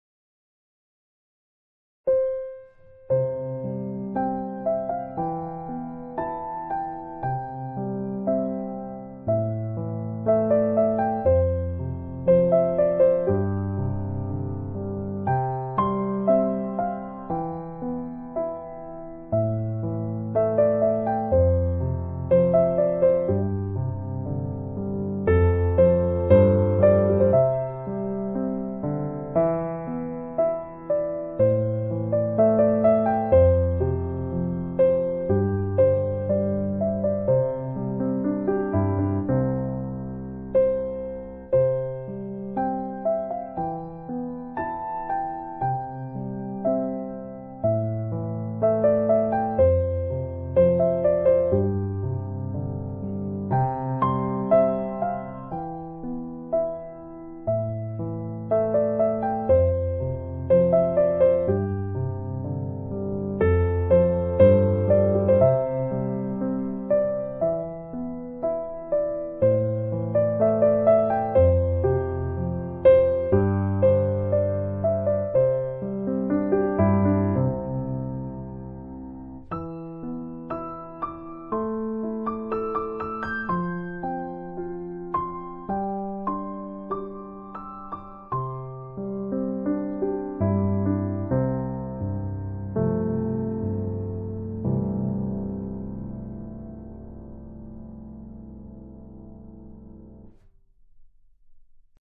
giai điệu du dương